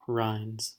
Ääntäminen
Ääntäminen UK US UK : IPA : /ɹʌɪndz/ US : IPA : /ɹaɪndz/ CA : IPA : /ɹaɪndz/ Haettu sana löytyi näillä lähdekielillä: englanti Käännöksiä ei löytynyt valitulle kohdekielelle.